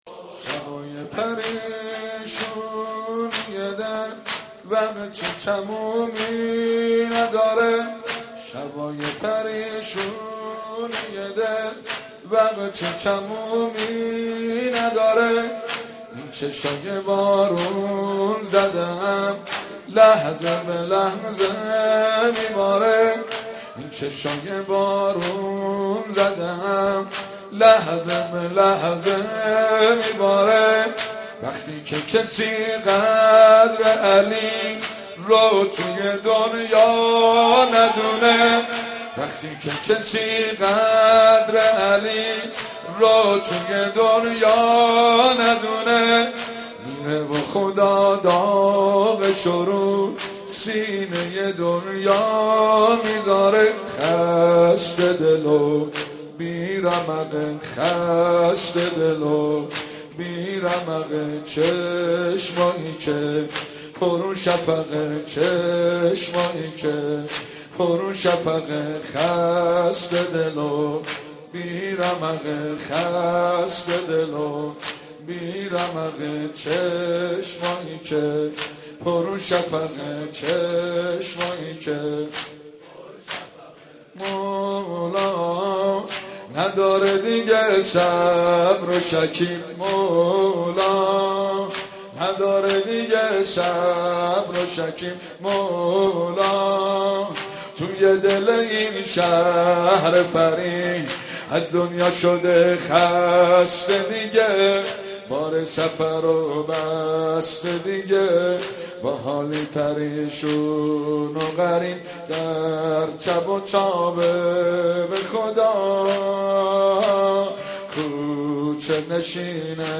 رمضان 89 - سینه زنی 1
رمضان 89 - سینه زنی 1 خطیب: حاج مهدی سلحشور مدت زمان: 00:03:32